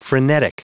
Prononciation du mot frenetic en anglais (fichier audio)
Prononciation du mot : frenetic